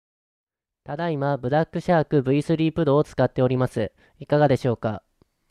マイク音声の比較
流石にオーディオミキサーを使用したマイクには劣りますが、ゲームチャットなどをするには十分な性能だと思います。
こちらはデフォルト設定ですが、BlackShark V3 Proは専用ソフトでマイク音声を調整できるので、拘りがある方は購入後に調整しましょう。